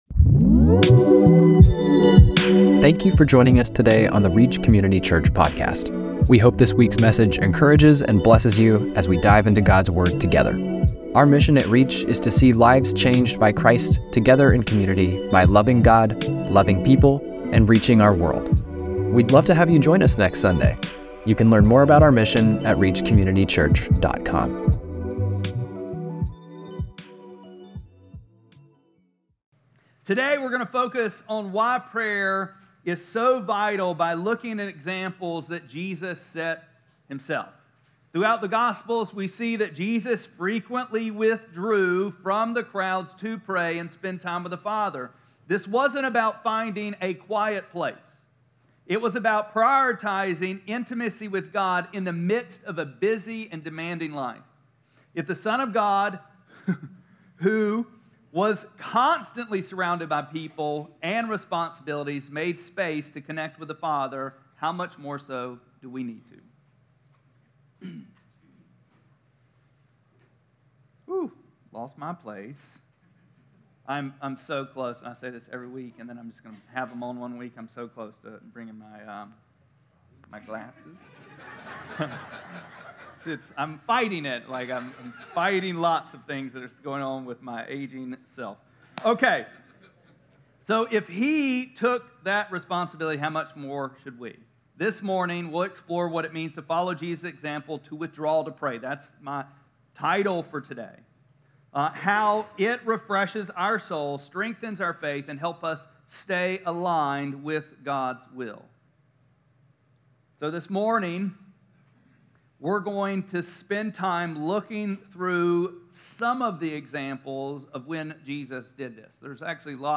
1-12-25-Sermon.mp3